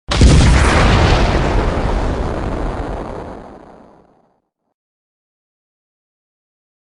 Nuclear Explosion
SFX
yt_A-dbHSY1_14_nuclear_explosion.mp3